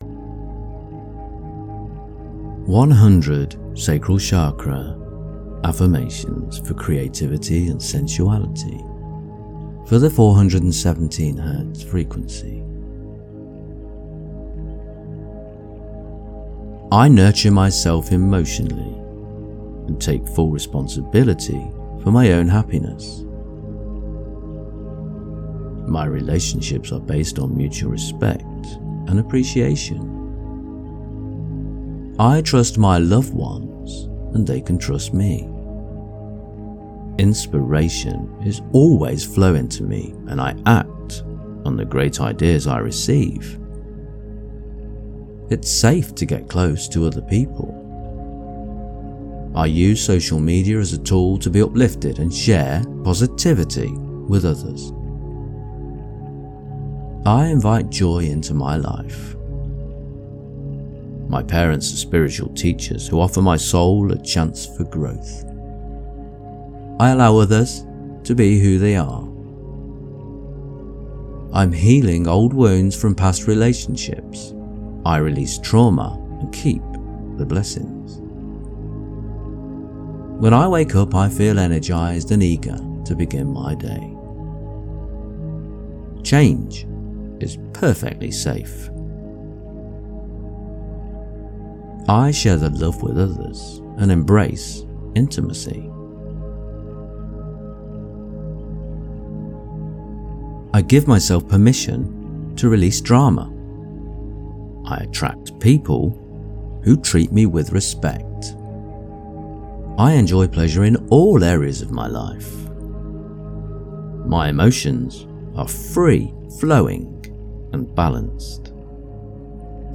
Delete emotional baggage | 417 HZ Solfeggio | Clear Negative Blocks | Affirmation Meditation for Stress Relief - Dynamic Daydreaming
417-sacral-affirmation.mp3